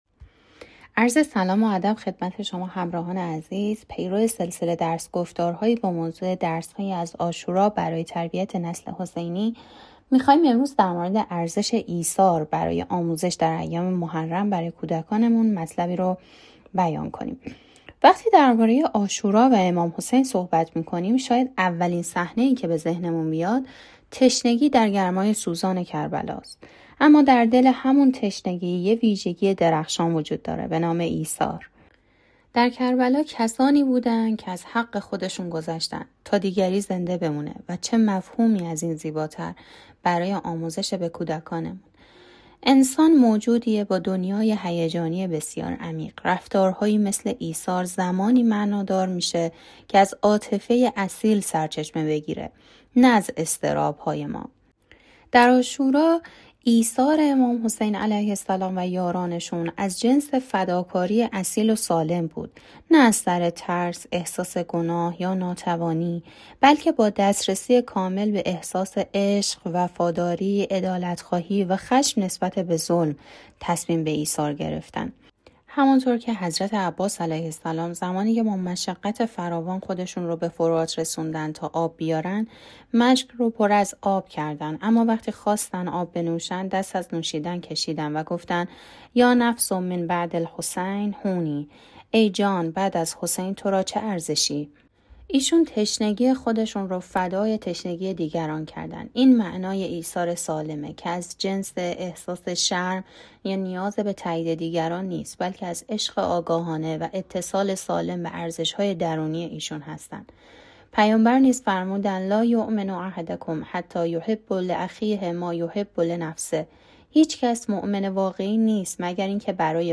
پیرو سلسله درس‌گفتار‌هایی با موضوع «درس‌هایی از عاشورا برای تربیت نسل حسینی»، می‌خواهیم در این قسمت، «ارزش ایثار» را به منظور آموزش این مفهوم به کودکان در ایام محرم تبیین کنیم.